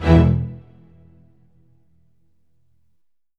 Orchestral Hits
ORCHHIT E2-L.wav